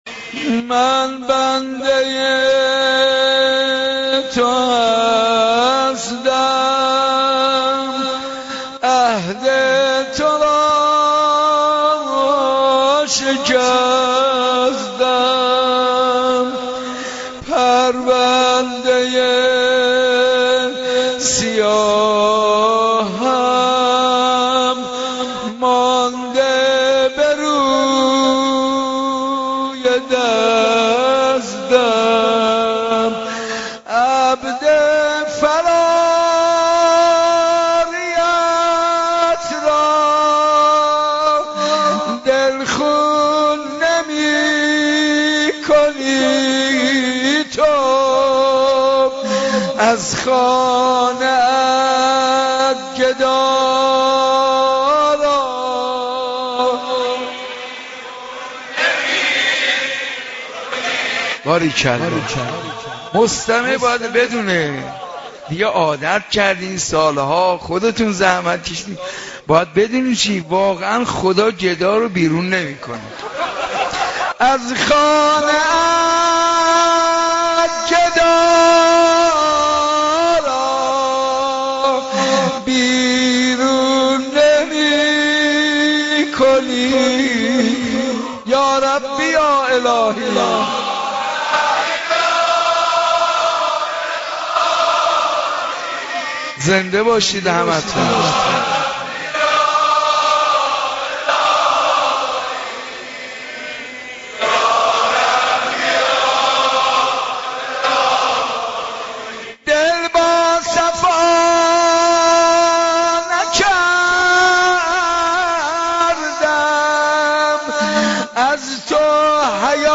در شب 10 رمضان 90